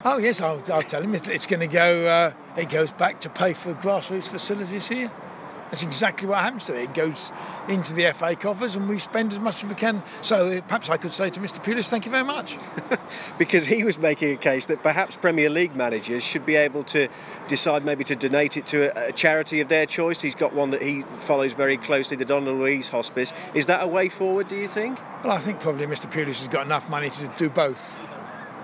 FA chairman Greg Dyke to explain how Tony Pulis' £8,000 fine will be spent, as he attends the opening of the new Birmingham FA HQ.